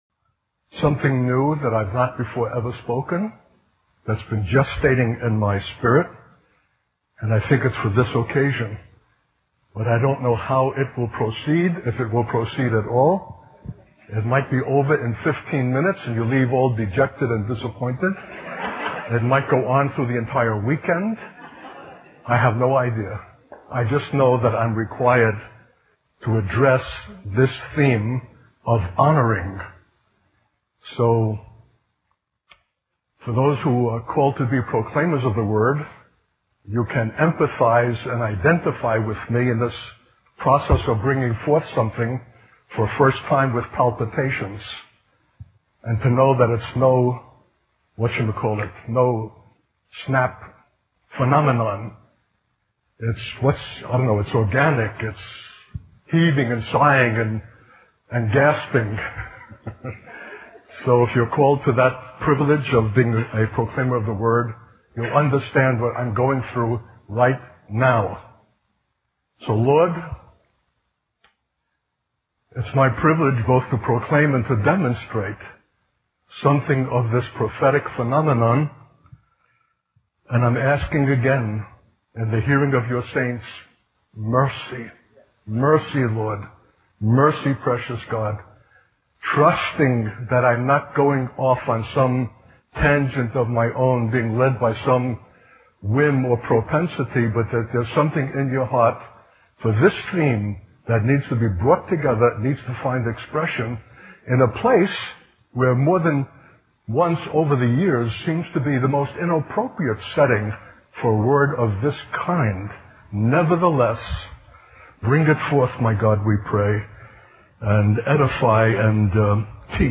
A 2005 message.